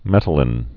(mĕtl-ĭn, -īn)